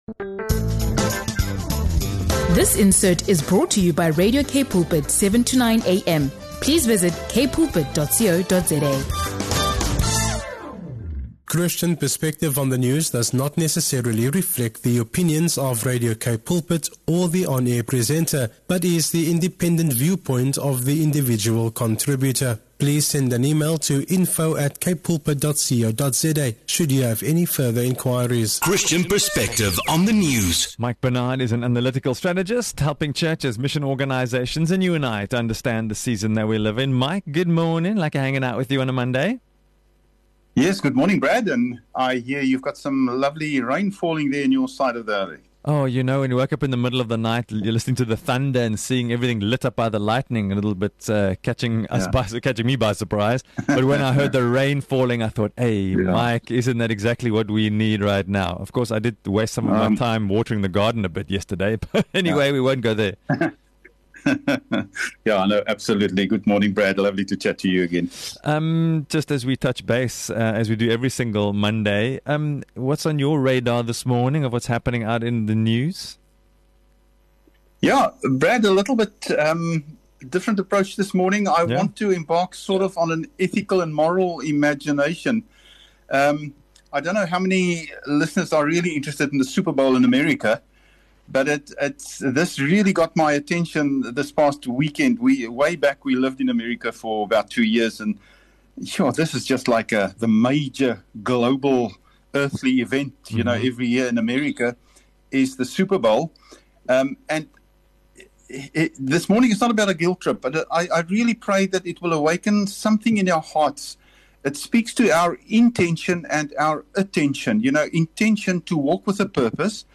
In this powerful Monday conversation